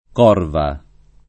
Corva [ k 0 rva ] top. (Friuli)